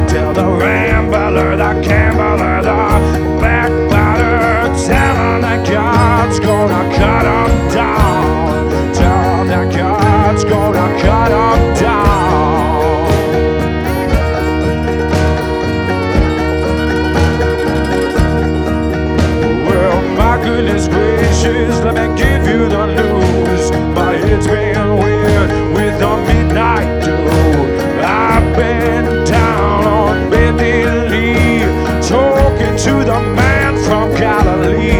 Жанр: Фолк / Кантри